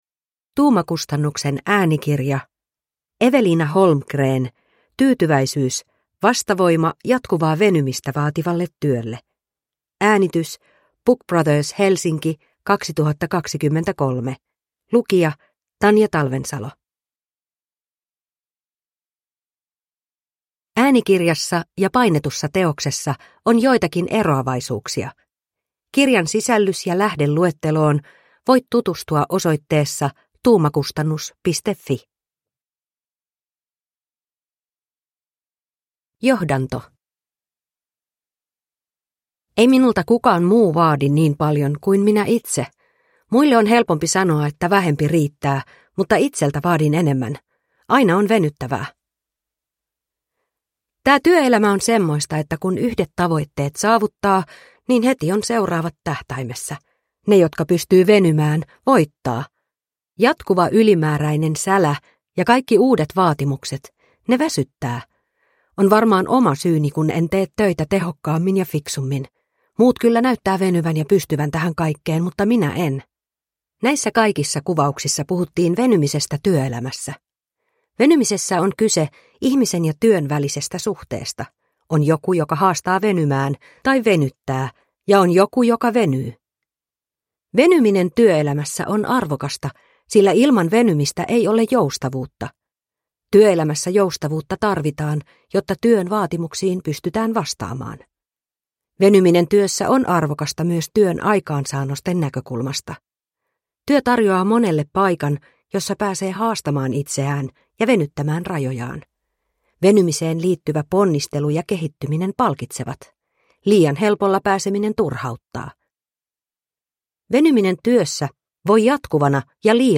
Tyytyväisyys – Ljudbok – Laddas ner